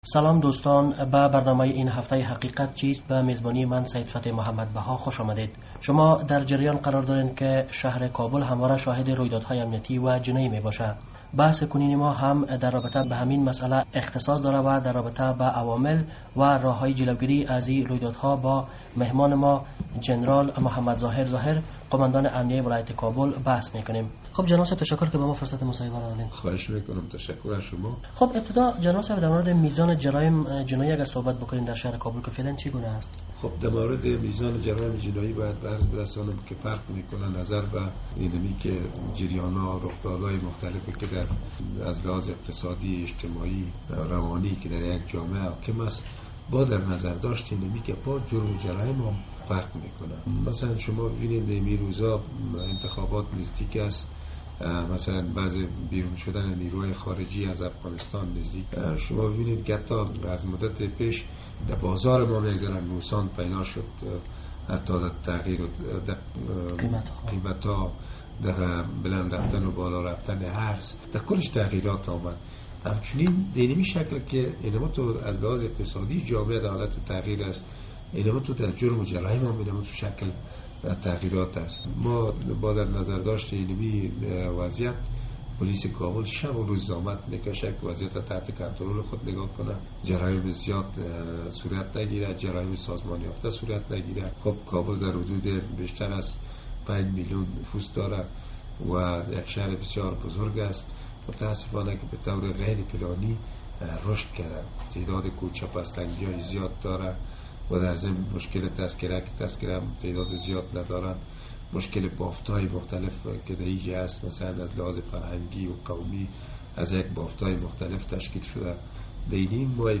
در برنامه امروز برنامهء حقیقت چیست، جنرال ظاهر ظاهر قوماندان امنیهء ولایت کابل مهمان ما است...